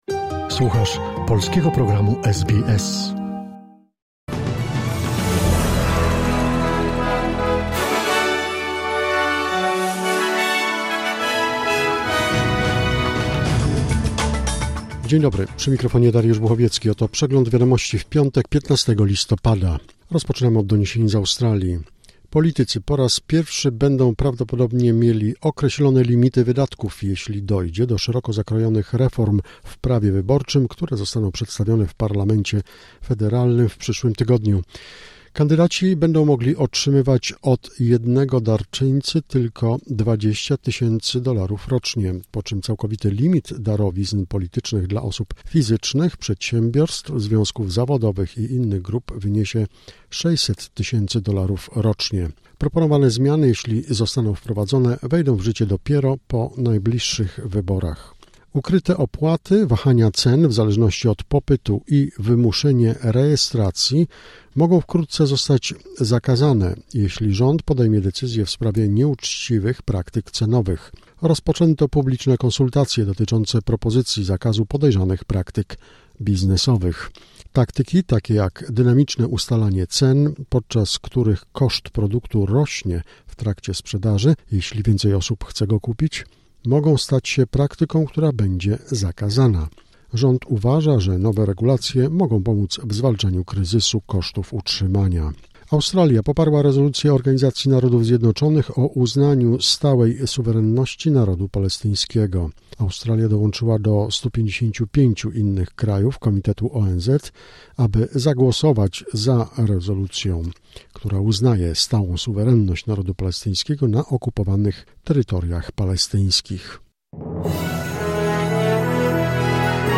Skrót najważniejszych doniesień z Australii i ze świata, w opracowaniu polskiej redakcji SBS.